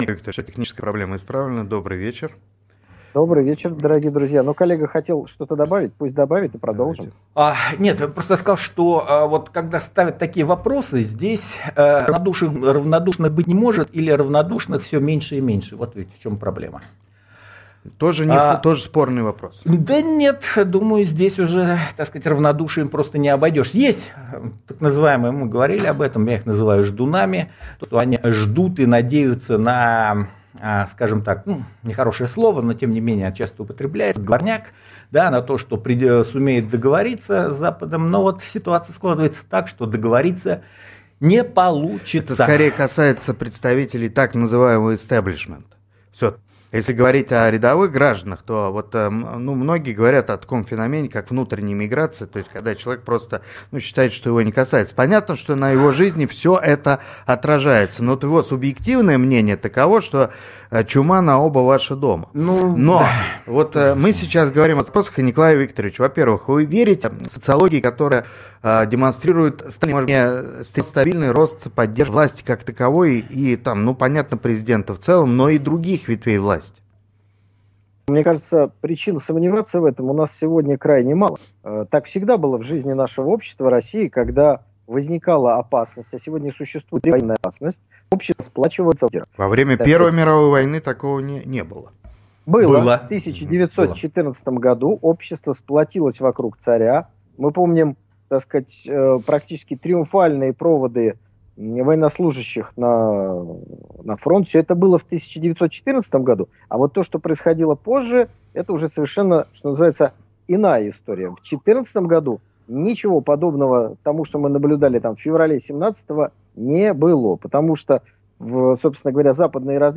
Острая, я бы даже сказал, острейшая дискуссия состоялась в эфире ИА «Аврора», когда разговор зашёл про сегодняшнюю политическую систему России в условиях СВО.
Вот обо всём этом мы и поговорили в прямом эфире ИА «Аврора».